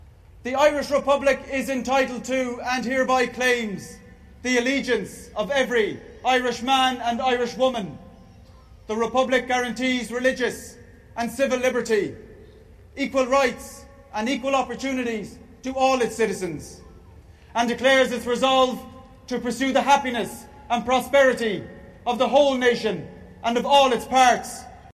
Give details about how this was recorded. Thousands attended the event at the GPO on O’Connell Street, including the Taoiseach, Tánaiste, Lord Mayor and President Michael D. Higgins.